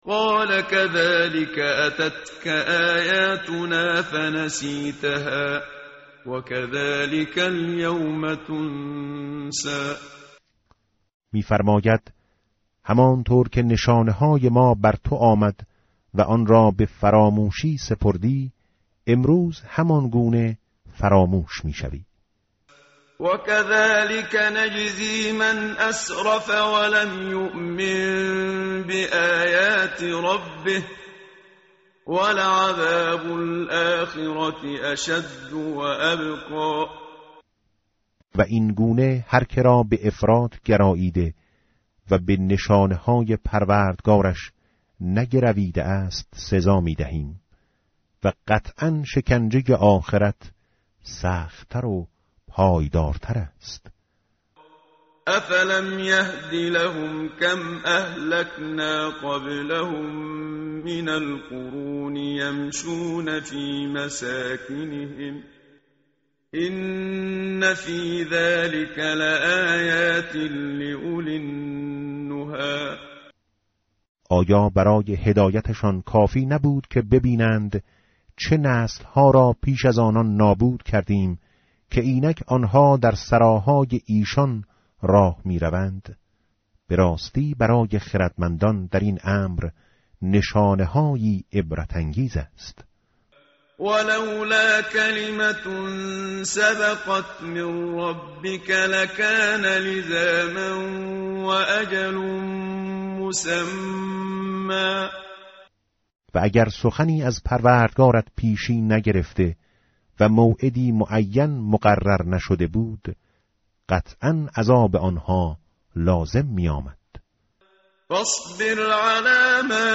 tartil_menshavi va tarjome_Page_321.mp3